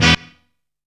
HELP HIT.wav